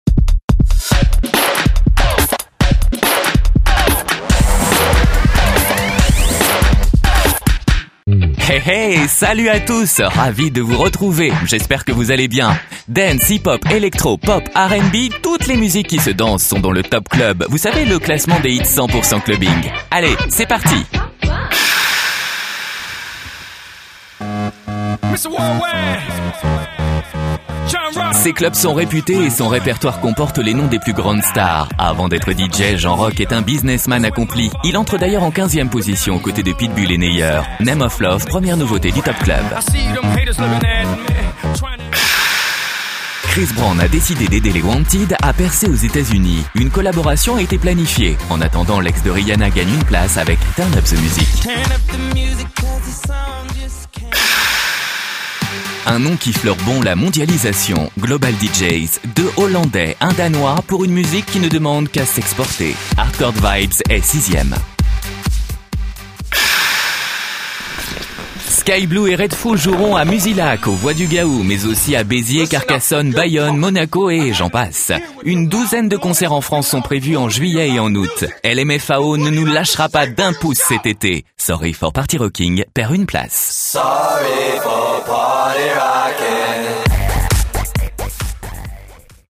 TOP CLUB D17 - Comédien voix off
Genre : voix off.